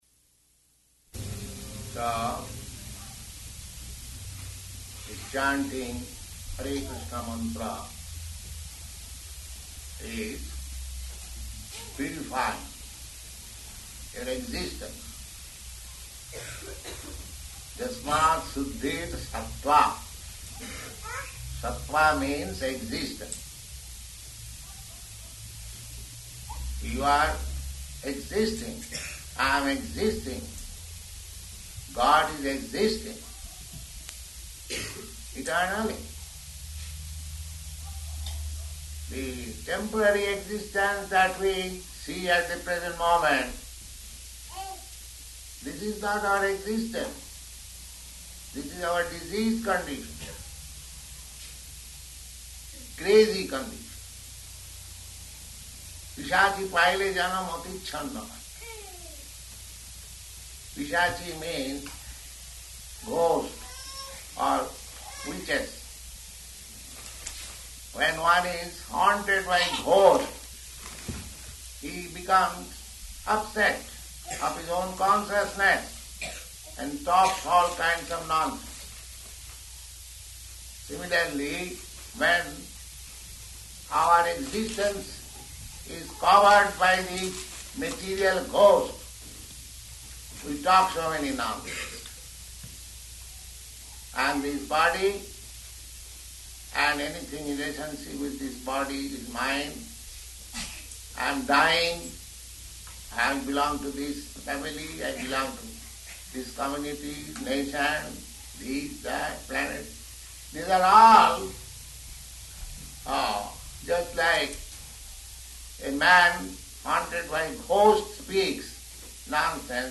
Initiation Lecture
Initiation Lecture --:-- --:-- Type: Initiation Dated: December 26th 1969 Location: Boston Audio file: 691226IN-BOSTON.mp3 Prabhupāda: So this chanting Hare Kṛṣṇa mantra is purifying your existence.